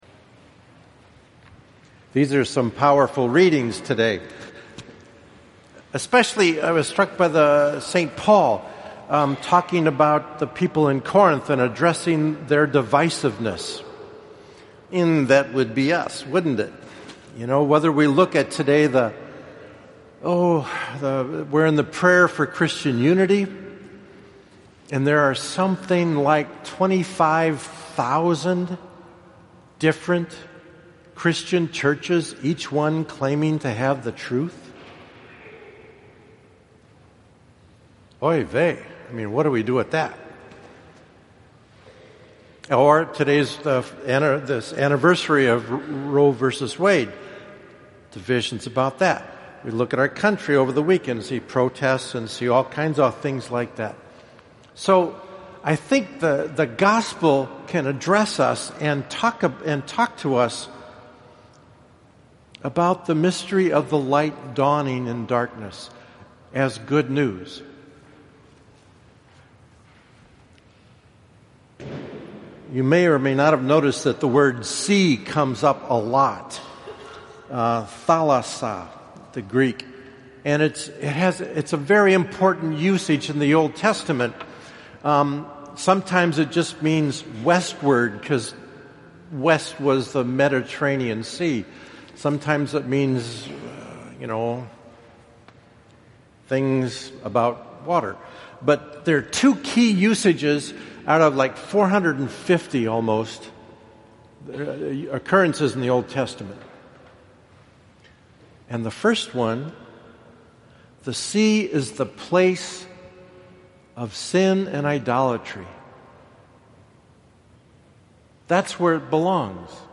I concluded my homily last Sunday with this: